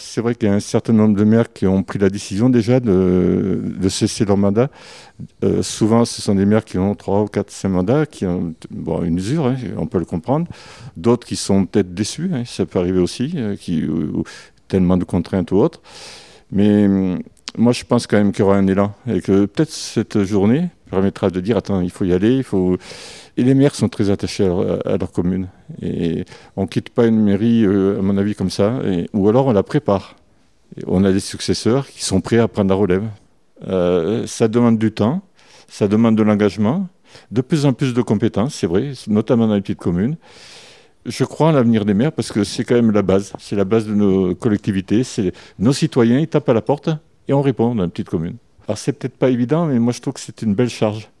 Un atelier autour des municipales de 2026 : pour certains, ce salon sera le dernier et pour d’autres il est justement l’occasion de préparer sa candidature. Un rôle de maire essentiel et qui doit encore attirer, espère Alain Astruc, maire de Peyre-en-Aubrac et président de l’association départementale des maires de France, organisateur de l’événement.